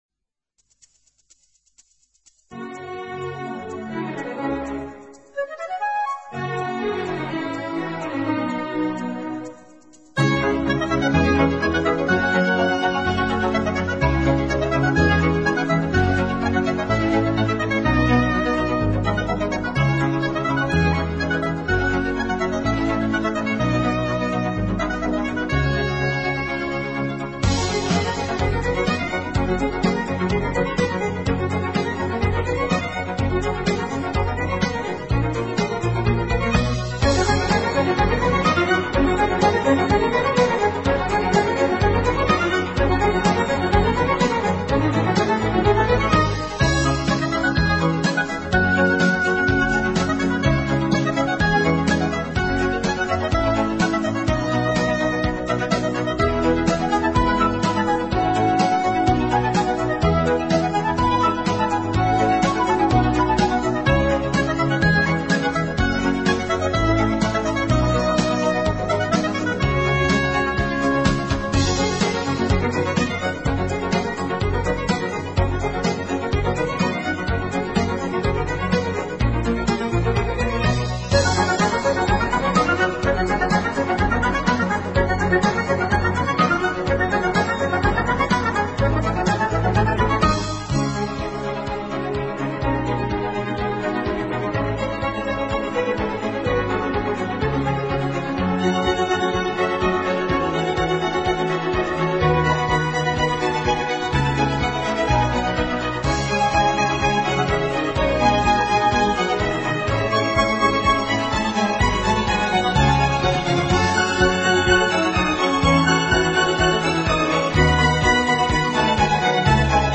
轻音乐专辑
音乐风格：Neo Classical，室内乐